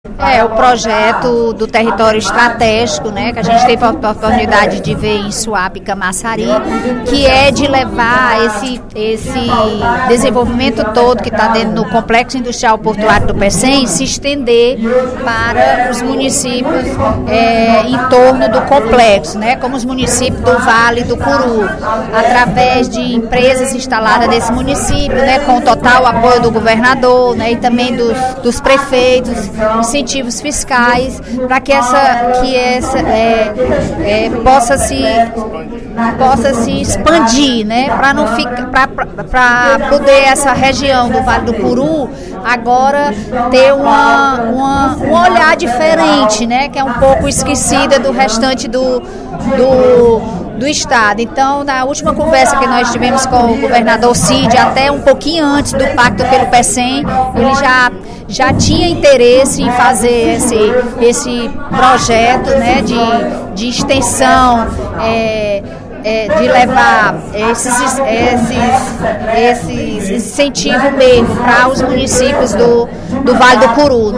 A deputada Bethrose (PRP) informou, durante pronunciamento na sessão plenária desta terça-feira (12/06), que ingressou com um projeto de lei para criar um território estratégico do Complexo Industrial e Portuário do Pecém (CIPP). O objetivo, segundo ela, é estender a uma área maior o desenvolvimento gerado pelo empreendimento.